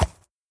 sounds / mob / horse / soft2.mp3
soft2.mp3